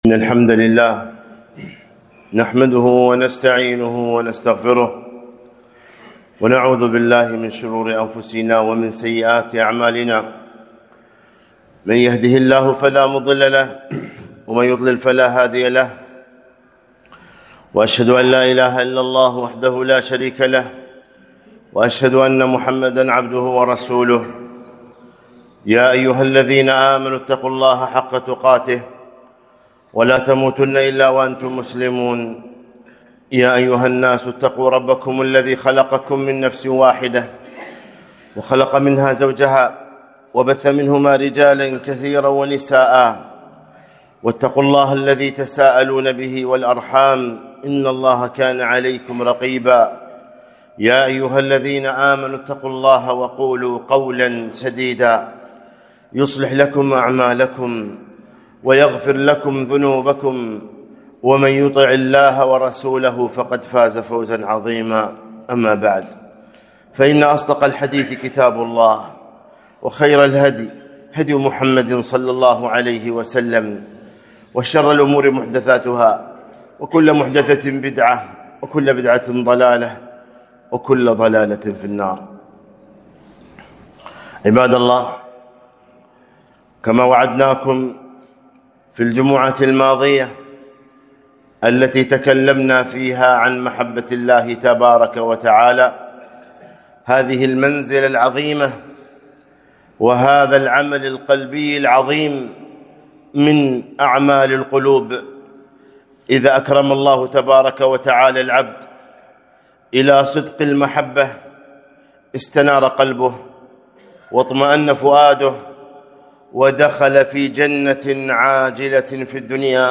خطبة - الأسباب الجالبة لمحبة الله عزوجل ج١